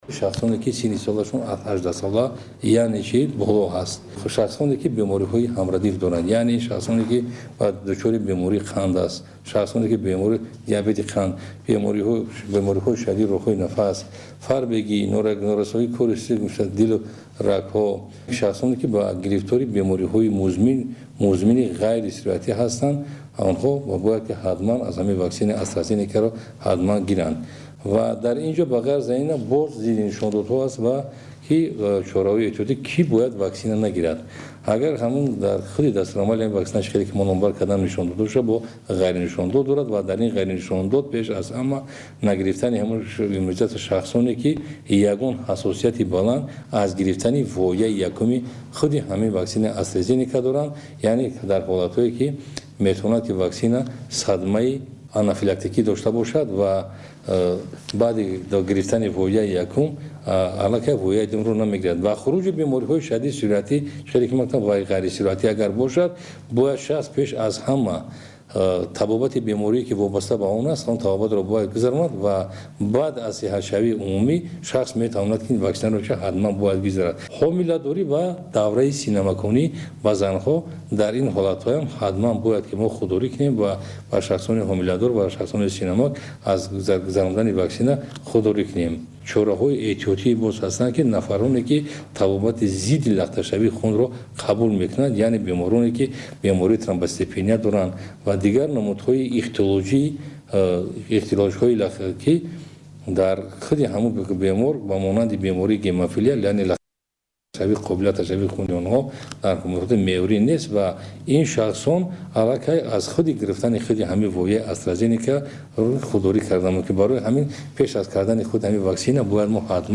Ба гузориши радиои тоҷикии Садои Хуросон, бар асоси тасмими Ситоди муқобила бо коронаи Тоҷикистон, тамомии афроди болои 18 сол бояд ваксина шаванд. Файзалӣ Саидзода, мудири маркази бехатарӣ аз сирояти бемориҳои Вазорати тандурустии Тоҷикистон дар бораи ваксина кардан дар кишвар тавзеҳ дод: